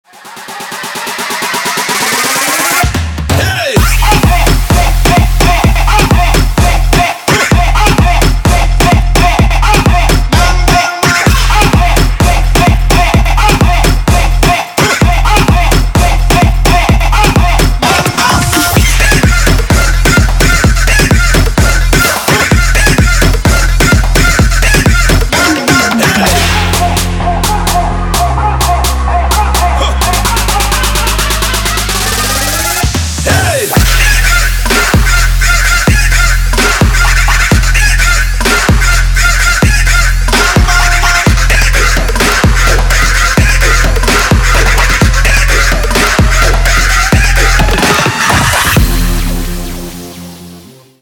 • Качество: 320, Stereo
dance